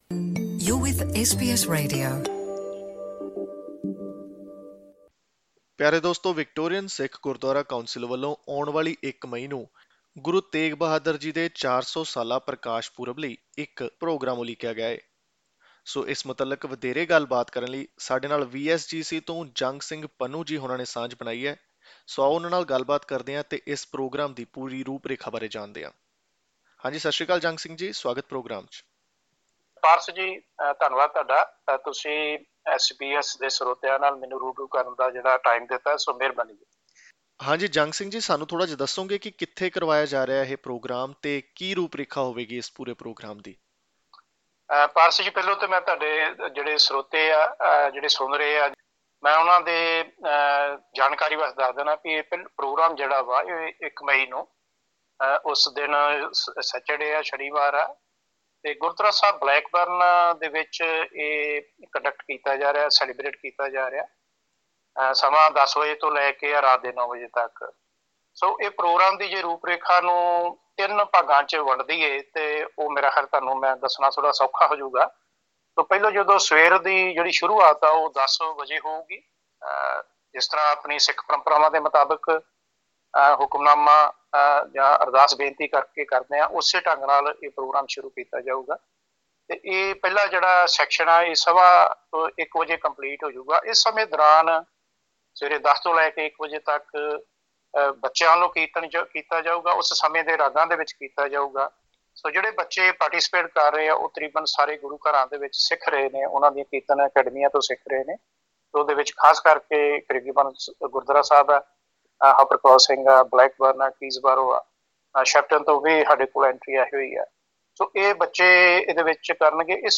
ਇੰਟਰਵਿਊ